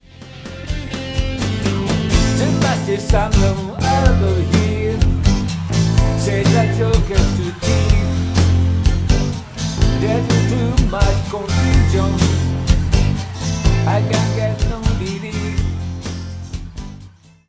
tónica la